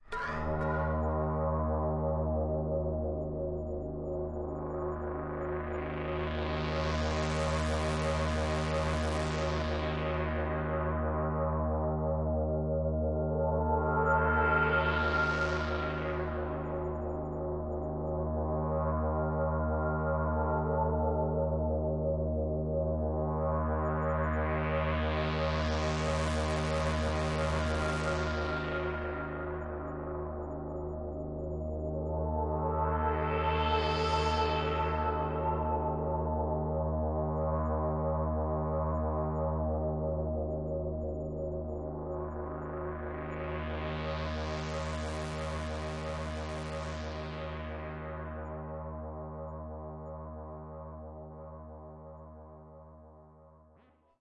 Part of a collection of synthetic drones and atmospheres.
标签： ambient drone atmosphere
声道立体声